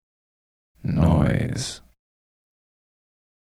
"noise" 3 sec. stereo 70k
noise.mp3